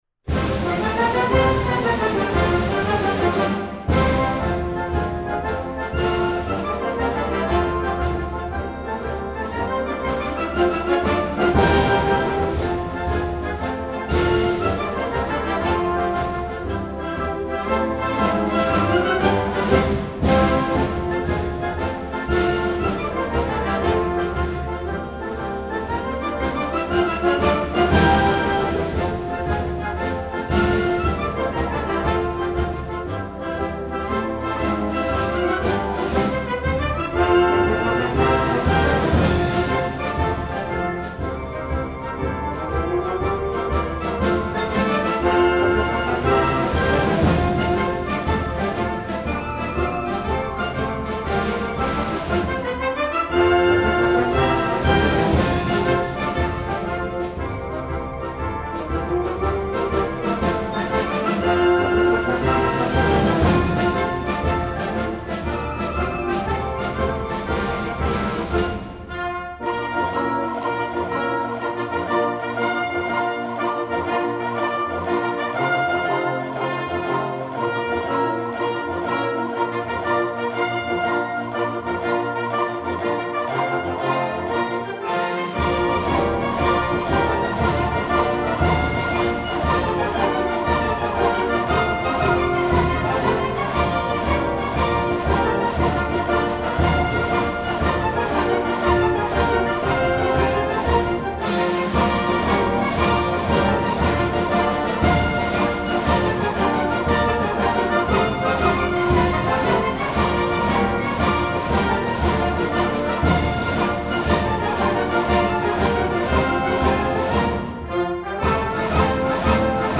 instructional, american